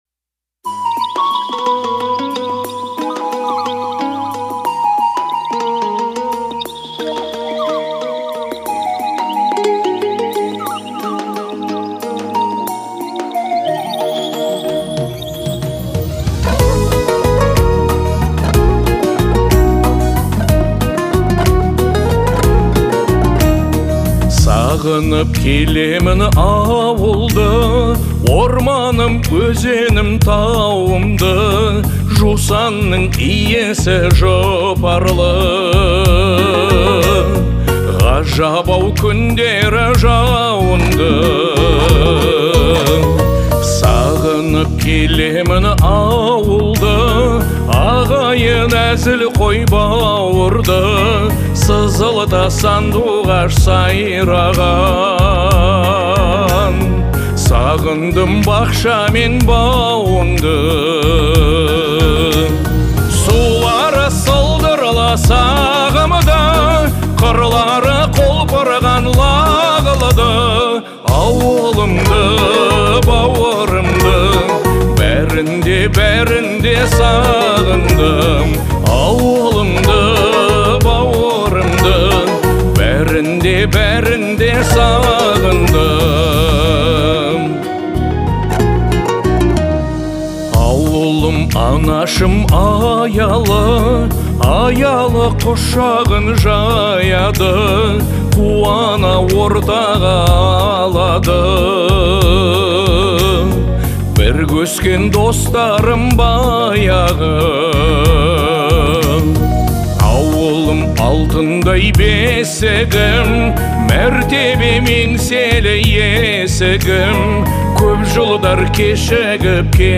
это трогательная песня в жанре казахской народной музыки